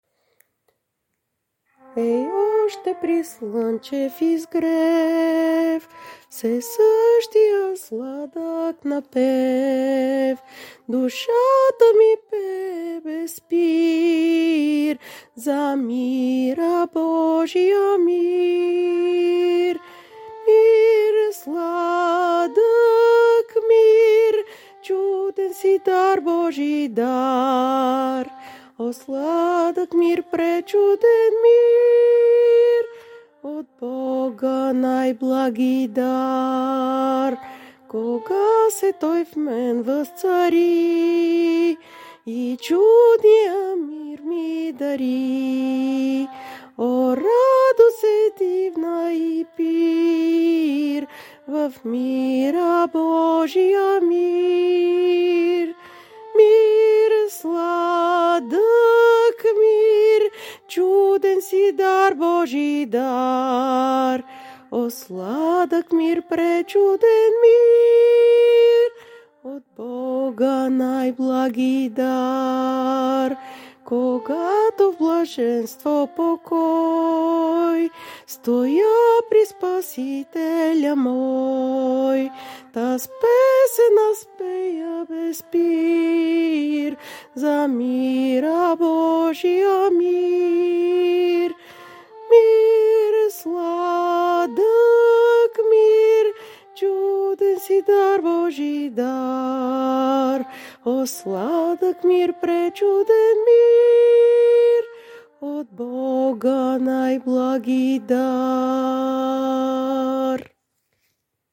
piano, keyboard, keys
Изпълнение на живо: